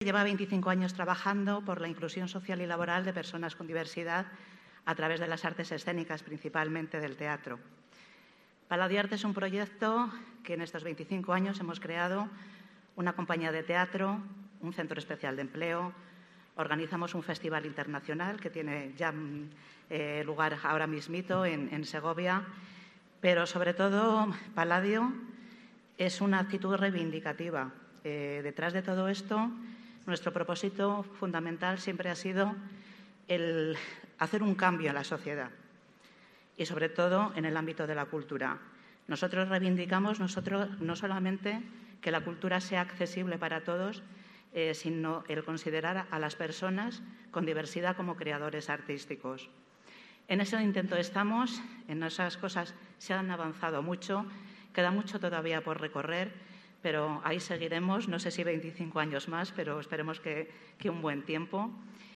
Los Premios Solidarios ONCE Edición Especial 2021Abre Web externa en ventana nueva homenajearon, el pasado 1 de diciembre, la solidaridad y fuerza de la sociedad castellano y leonesa, durante la ceremonia de entrega de galardones que se celebró en el Teatro Zorrilla de Valladolid.